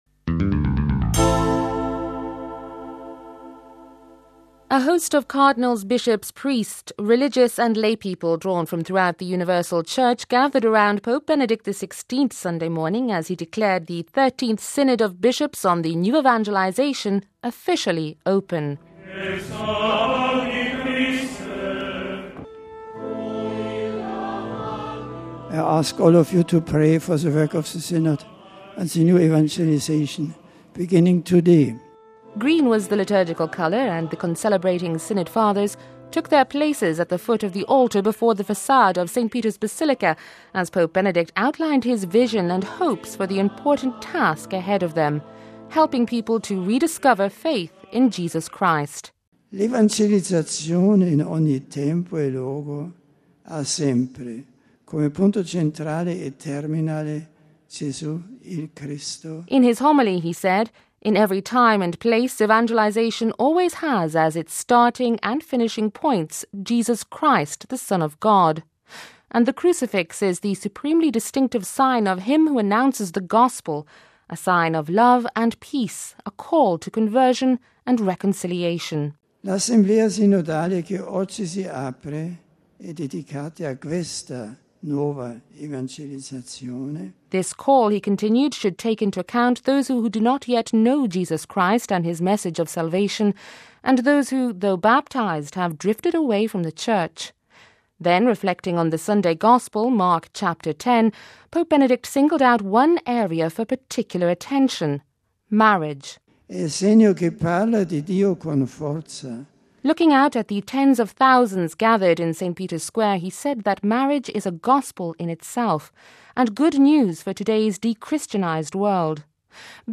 (Vatican Radio) – A host of cardinals, bishops, priests, religious and lay people drawn from throughout the Universal Church gathered around Pope Benedict XVI Sunday morning as he declared the Thirteenth Synod of Bishops on the New Evangelisation, officially open.
reports